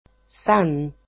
SOUND EXAMPLES in MODERN GREEK
[san]